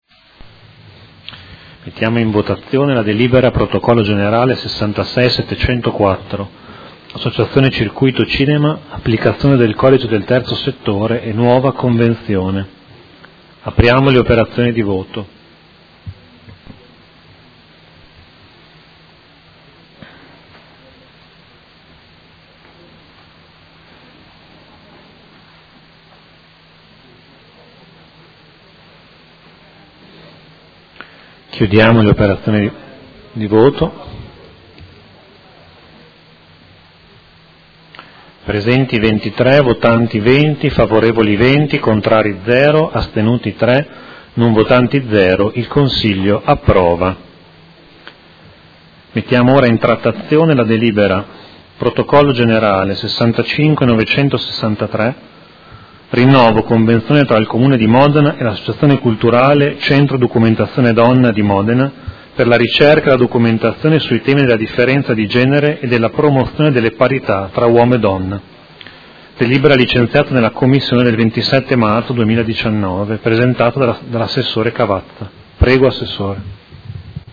Seduta del 28/03/2019. Mette ai voti proposta di deliberazione: Associazione Circuito Cinema – Applicazione del codice del terzo settore e nuova convenzione
Vice Presidente